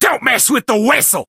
buzz_kill_vo_01.ogg